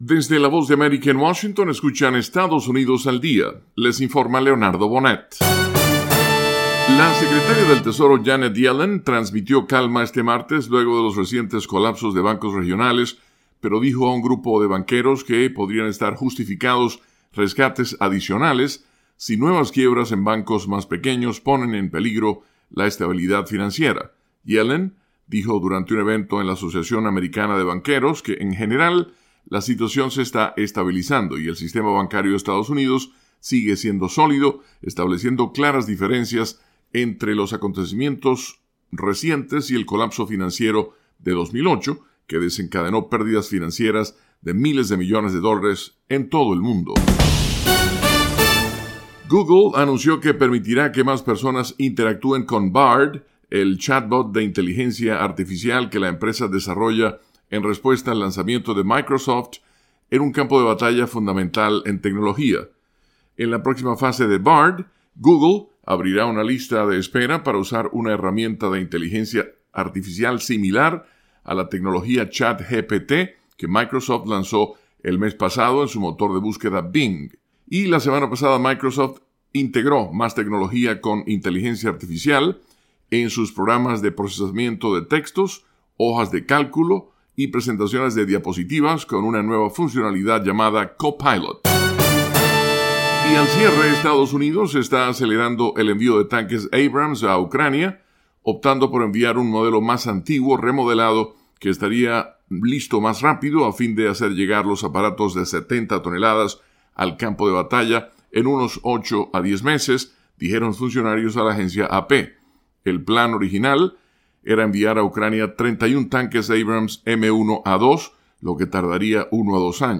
Resumen con algunas de las noticias más importantes de Estados Unidos